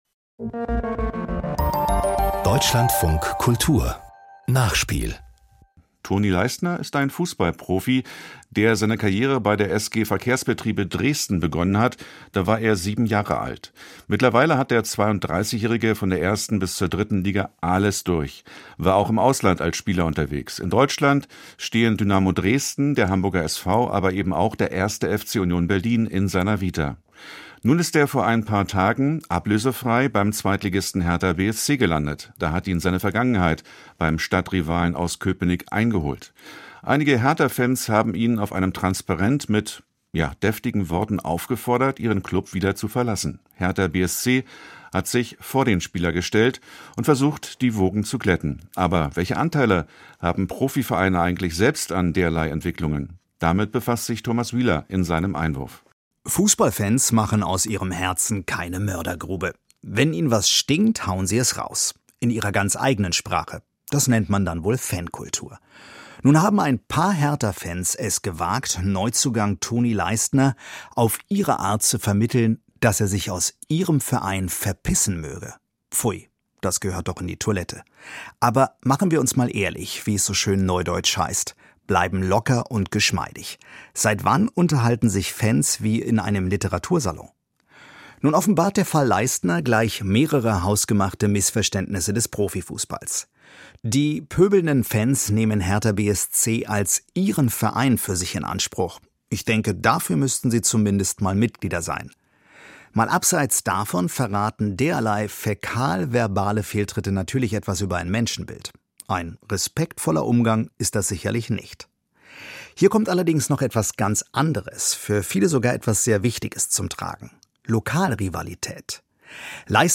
Kommentar zum Fall Toni Leistner - Die Rivalen-Nummer ist nicht mehr zeitgemäß